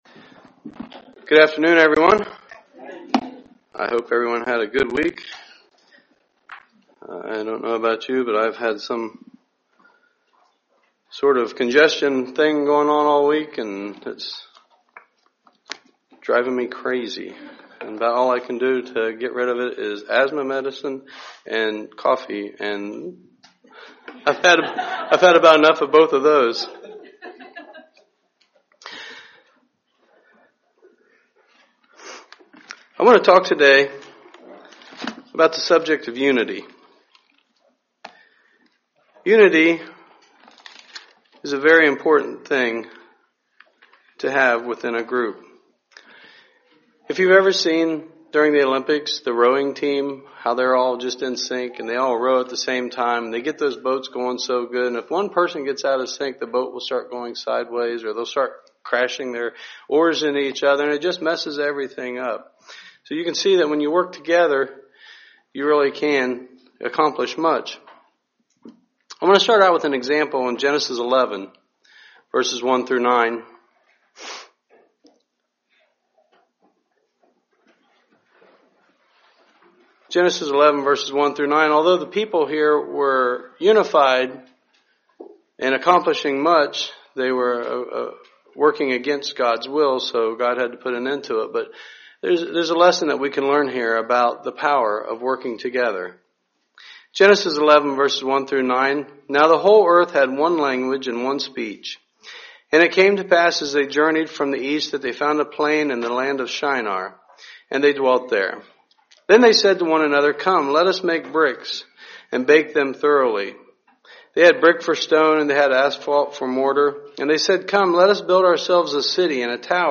God calls the Church to be unified. This sermon depicts some of the benefits on unity and also some ideas for church unity.